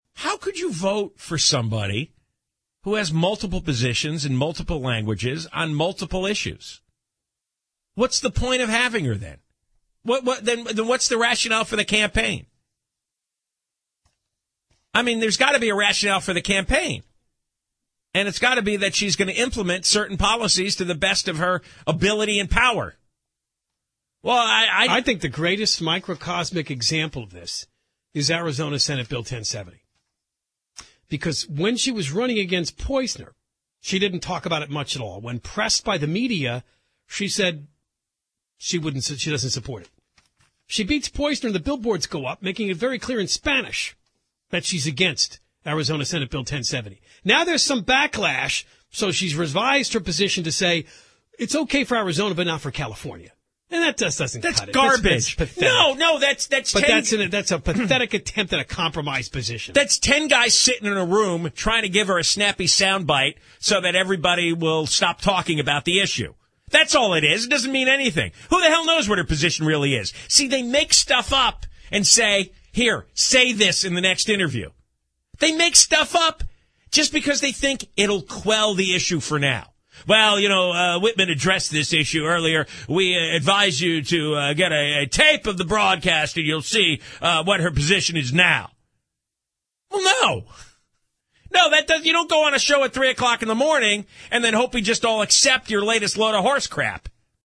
here are those “shock radio jocks,” discussing that conversation after they replayed it last month.